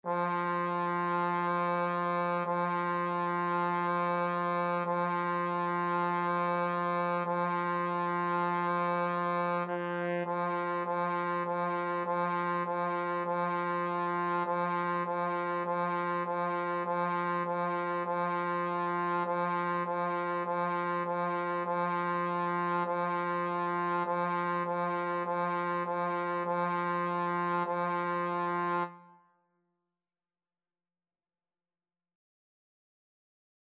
4/4 (View more 4/4 Music)
F4-F4
Beginners Level: Recommended for Beginners
Instrument:
Trombone  (View more Beginners Trombone Music)
Classical (View more Classical Trombone Music)